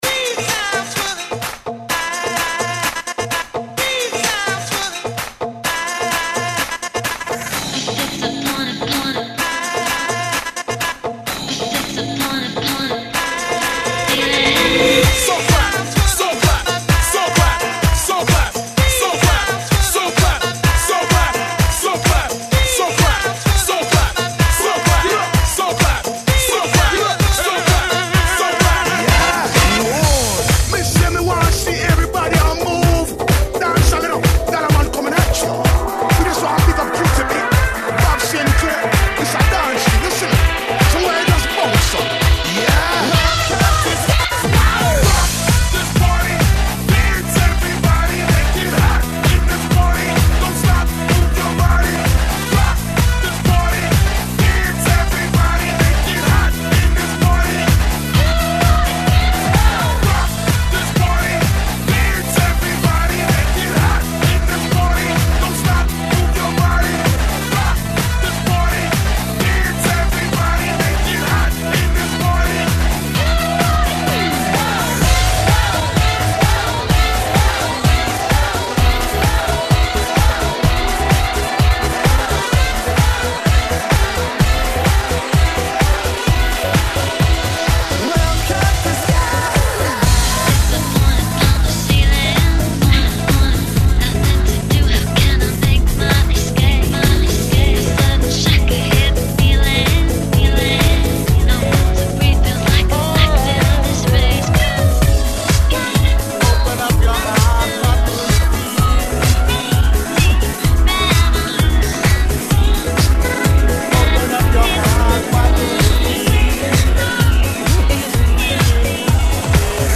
Intro Mix
Il y a je pense au moin 25 songs dans cette intro.